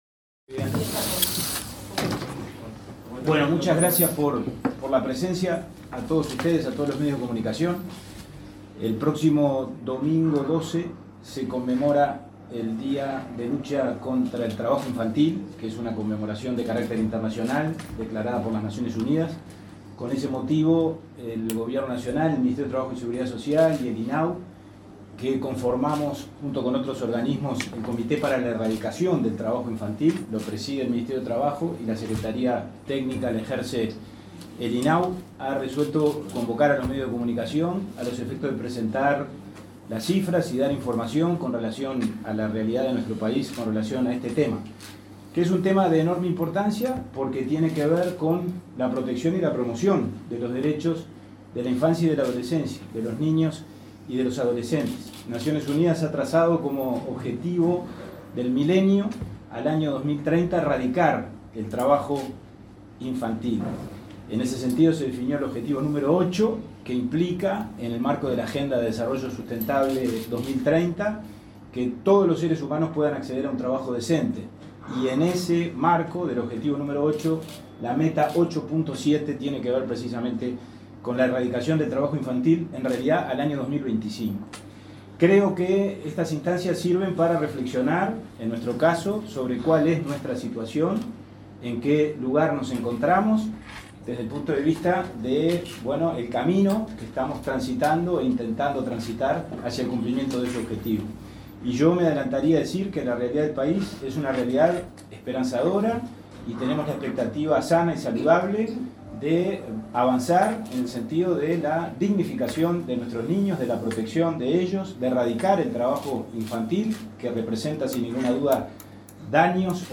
Palabras de autoridades en presentación de cifras relativas a trabajo infantil y adolescente
Palabras de autoridades en presentación de cifras relativas a trabajo infantil y adolescente 09/06/2022 Compartir Facebook X Copiar enlace WhatsApp LinkedIn El presidente del Instituto del Niño y el Adolescente del Uruguay (INAU), Pablo Abdala, la subinspectora del Ministerio de Trabajo Silvana Bittencourt y la inspectora nacional de Trabajo Infantil y Adolescente Ema Buxeda presentaron cifras relativas a esta problemática.